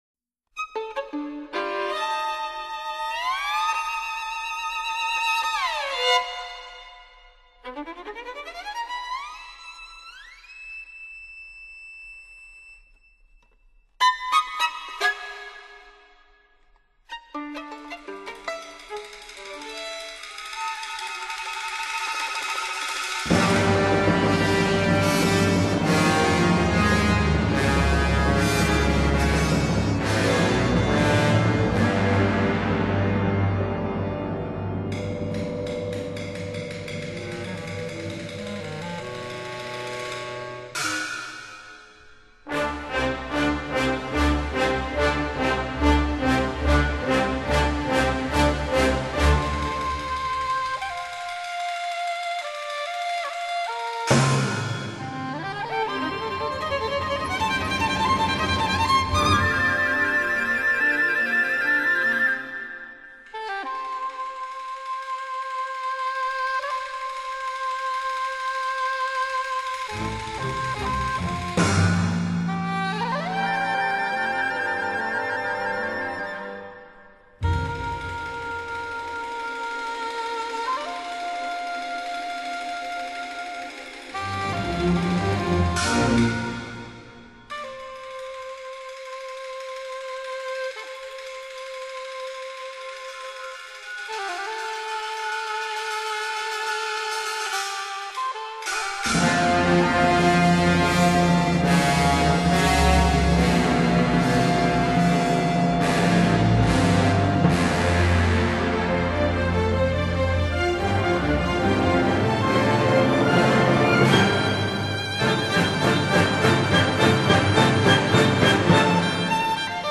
经过充满戏剧性的音乐处理，